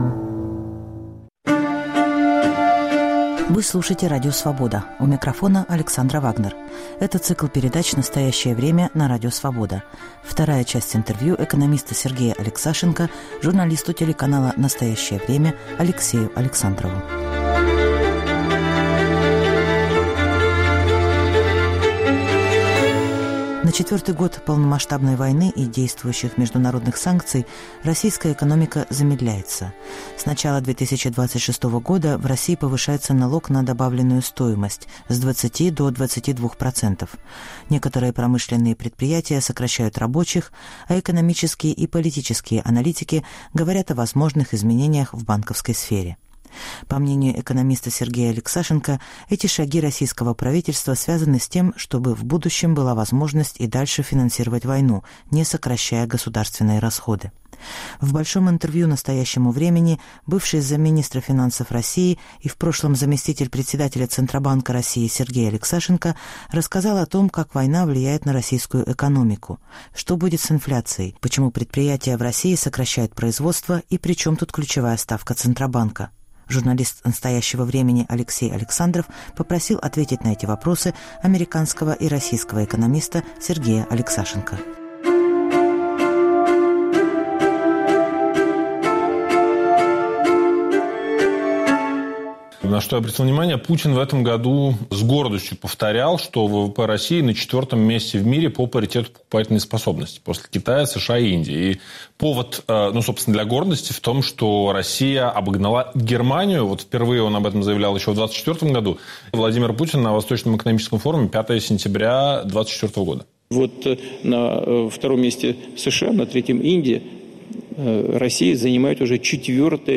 Журналист телеканала "Настоящее Время"